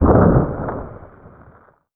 DEMOLISH_Short_05_mono.wav